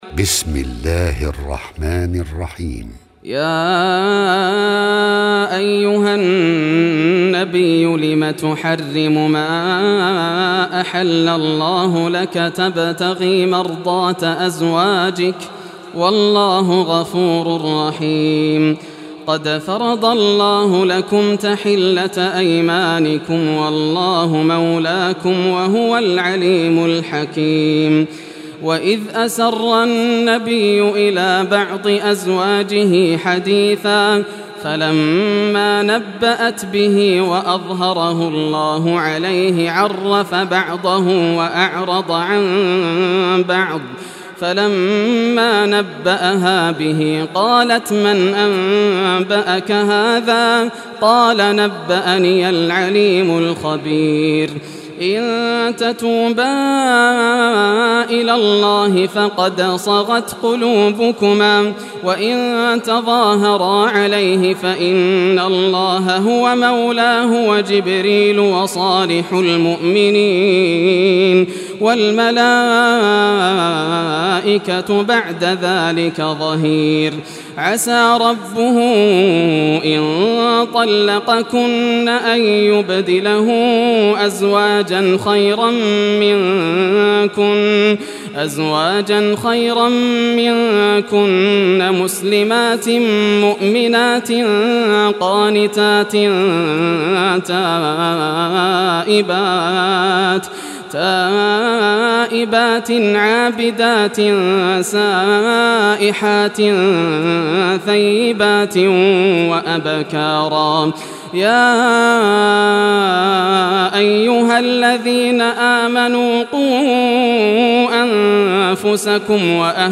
Surah At-Tahrim Recitation by Yasser al Dosari
Surah At-Tahrim, listen or play online mp3 tilawat / recitation in Arabic in the beauitful voice of Sheikh Yasser al Dosari.